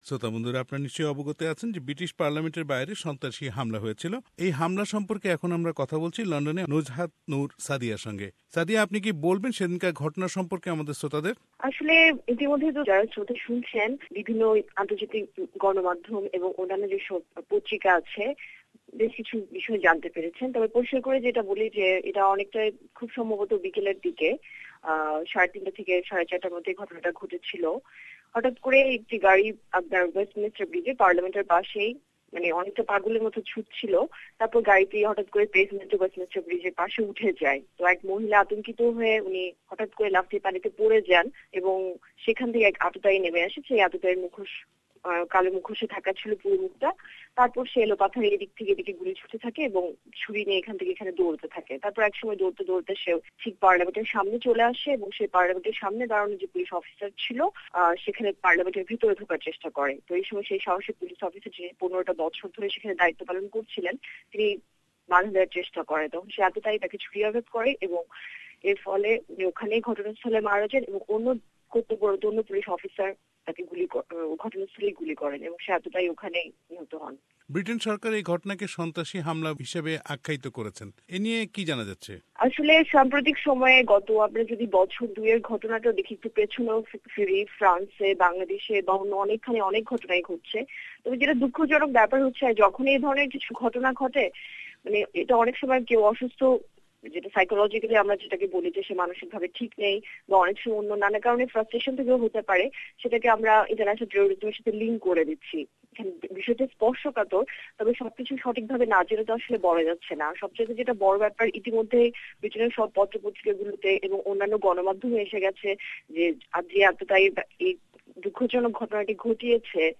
Terror attack at Westminster Bridge : Interview